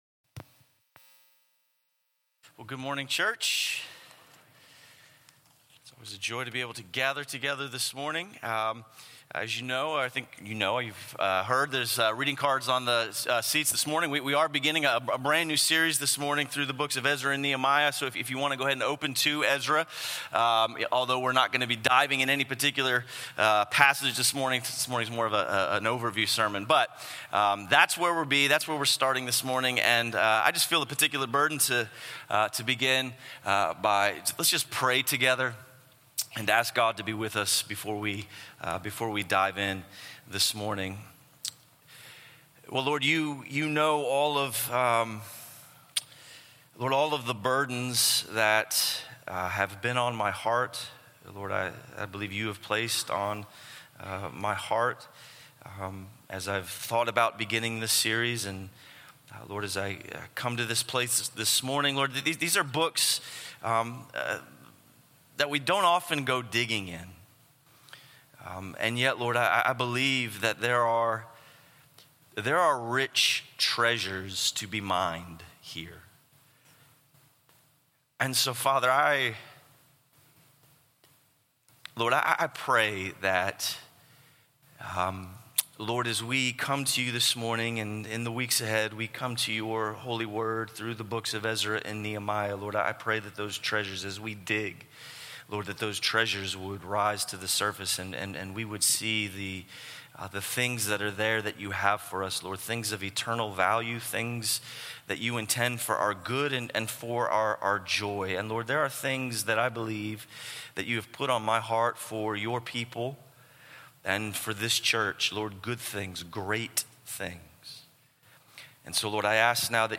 A sermon series through the books of Ezra and Nehemiah.